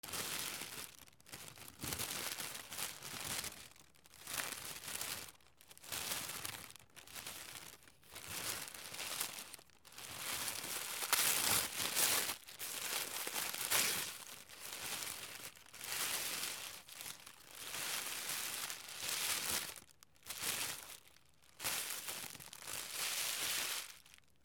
包み紙 柔らかい
/ M｜他分類 / L01 ｜小道具